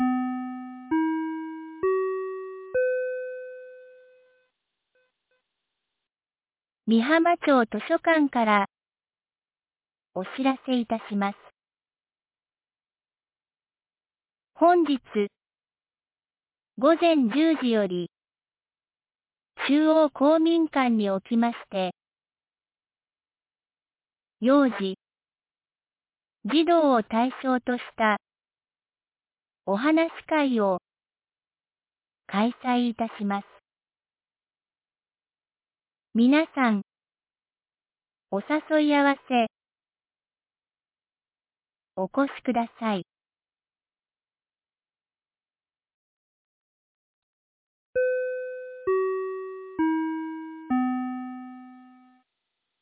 美浜町放送内容 2026年02月21日07時45分 【町内放送】図書館お話会（当日 | 和歌山県美浜町メール配信サービス
2026年02月21日 07時45分に、美浜町より全地区へ放送がありました。